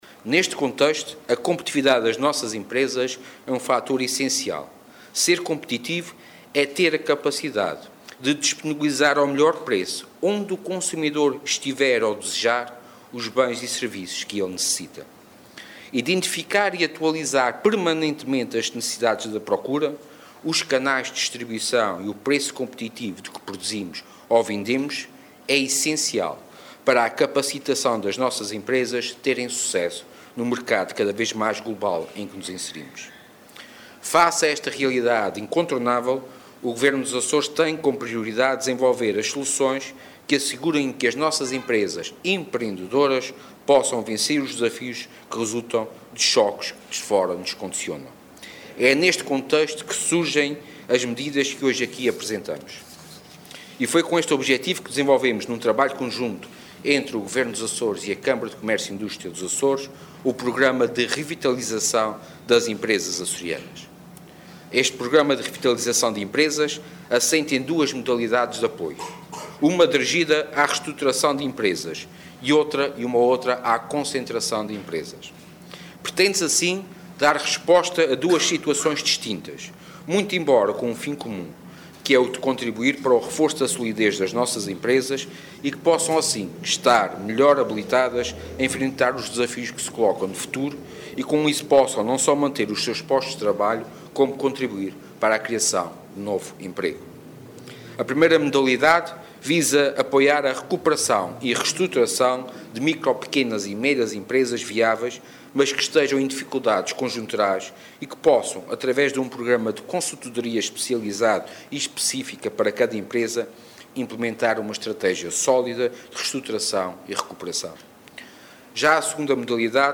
Sérgio Ávila, que falava na cerimónia de assinatura do protocolo de cooperação entre a Sociedade para o Desenvolvimento Empresarial dos Açores e a Câmara do Comércio e Industria dos Açores relativo à implementação do Programa de Revitalização de Empresas Açorianas, salientou, a propósito, que a competitividade das empresas é um fator essencial, uma vez que “ser competitivo é ter a capacidade de disponibilizar ao melhor preço, onde o consumidor estiver ou desejar os bens e serviços que ele necessita”.